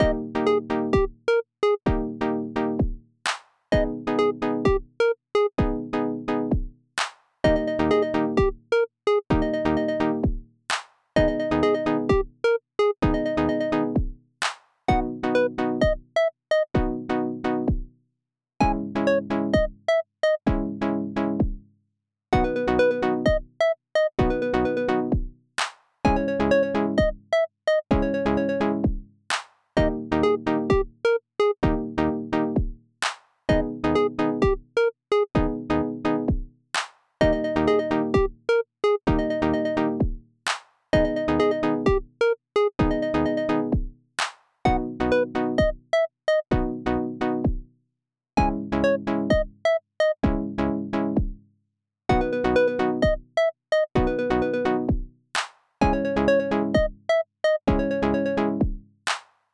BPM：129 キー：G#m ジャンル：あかるい、おしゃれ、みらい、ふゆ 楽器：シンセサイザー